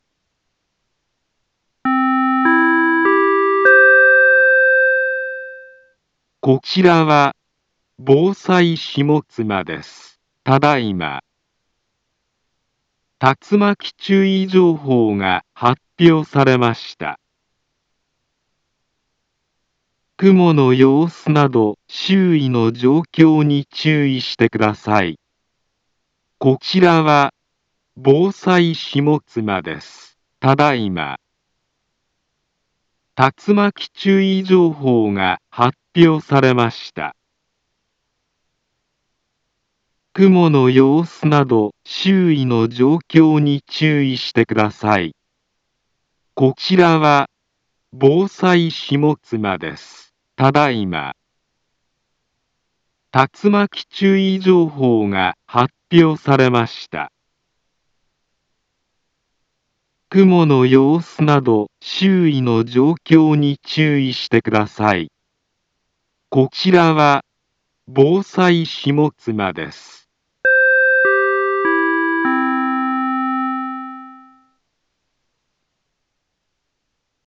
Back Home Ｊアラート情報 音声放送 再生 災害情報 カテゴリ：J-ALERT 登録日時：2021-08-30 20:05:13 インフォメーション：茨城県北部、南部は、竜巻などの激しい突風が発生しやすい気象状況になっています。